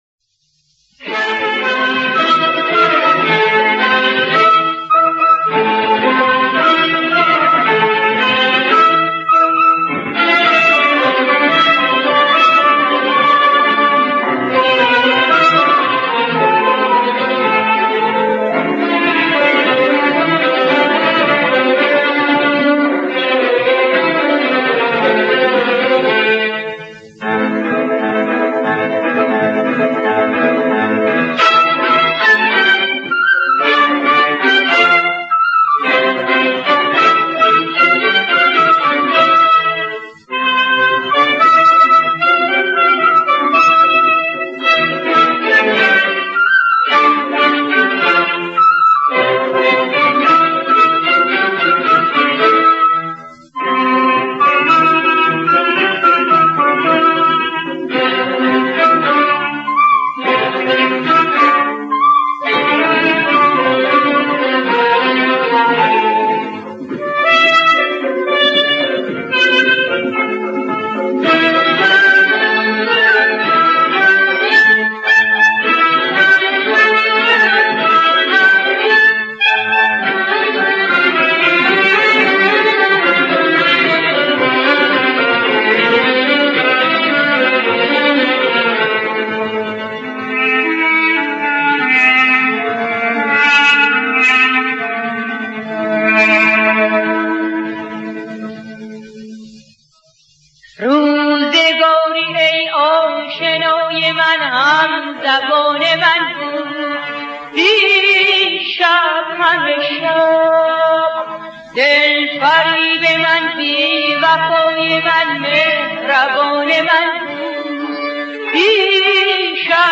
در مایه: شور / دشتی